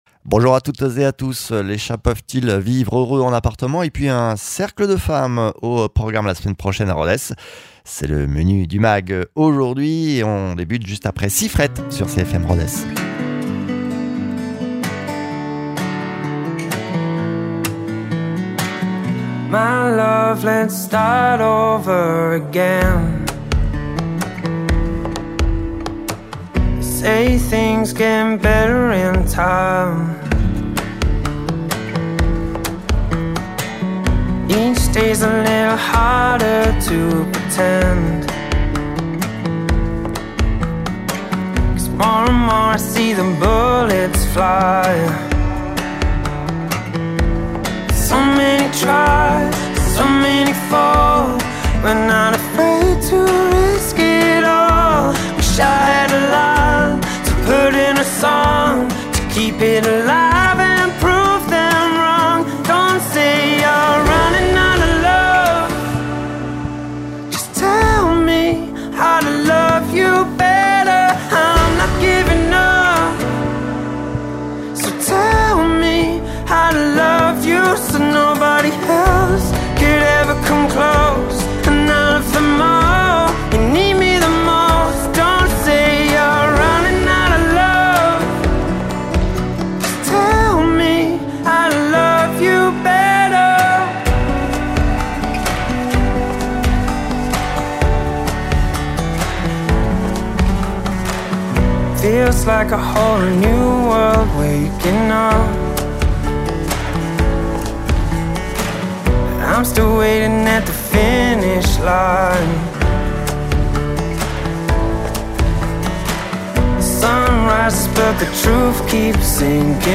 comportementaliste félin